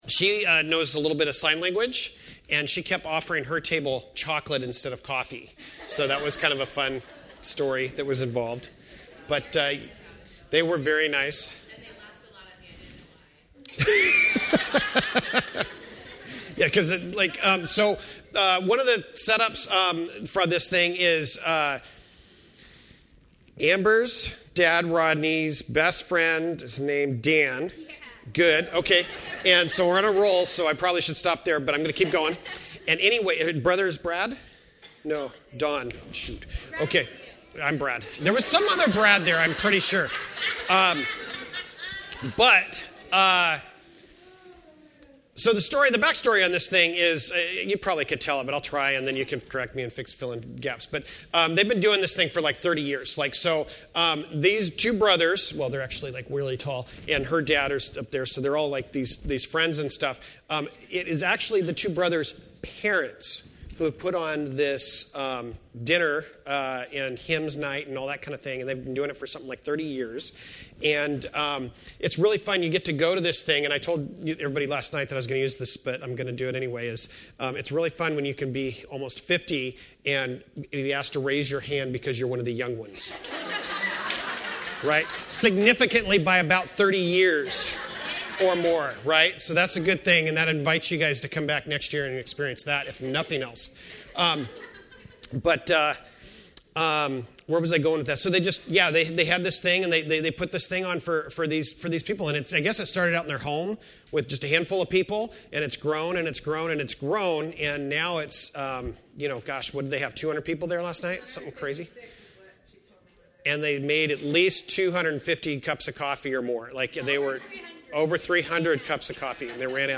Passage: Luke 2:36-38 Service Type: Sunday Morning